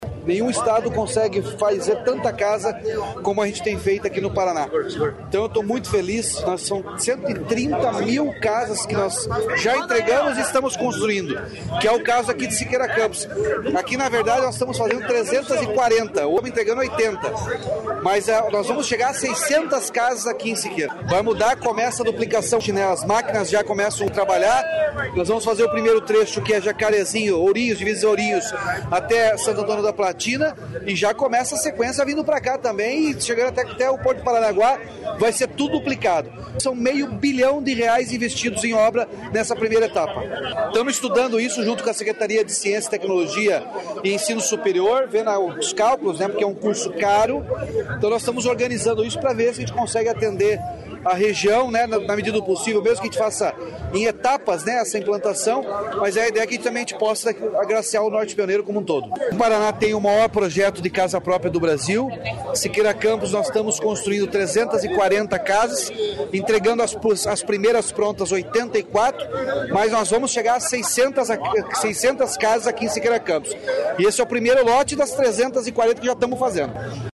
Sonora do governador Ratinho Junior sobre os investimentos em Siqueira Campos